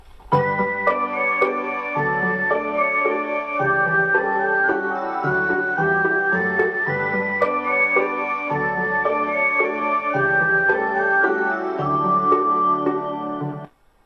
Using from effect sound collection.
Departure merody